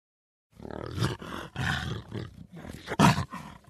Звуки ягуара
Два ягуара рычат